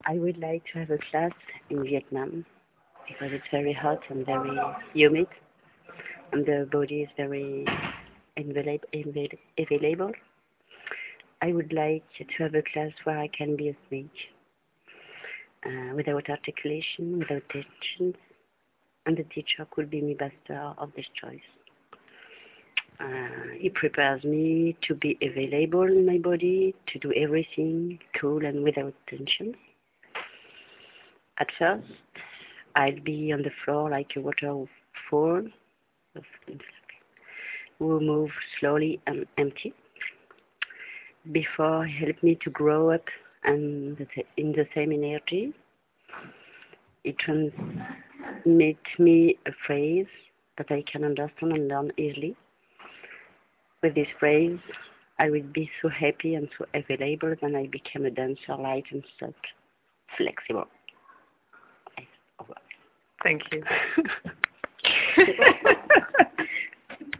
I do short interview of the teacher , with one question: Can you say to me which fantasy of workshop you have?